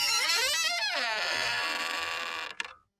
Creaky Door Open
An old door slowly creaking open on rusty hinges with wood groaning
creaky-door-open.mp3